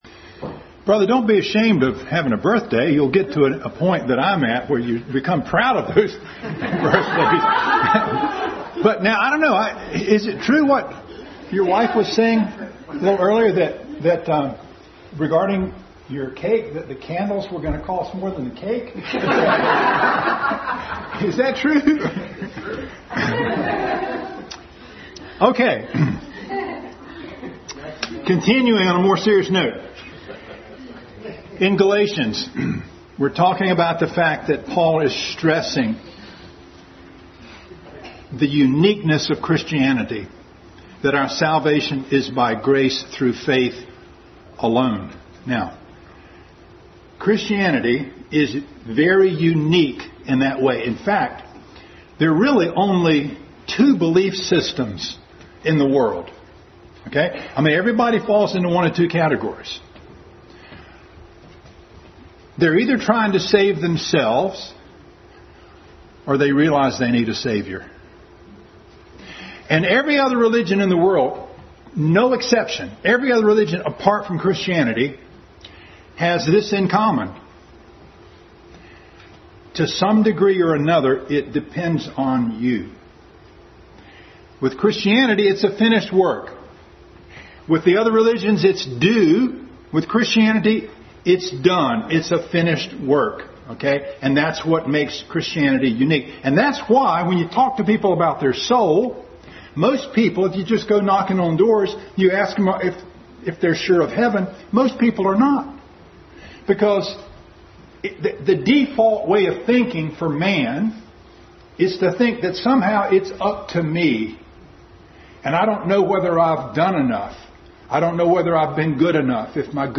Family Bible Hour message.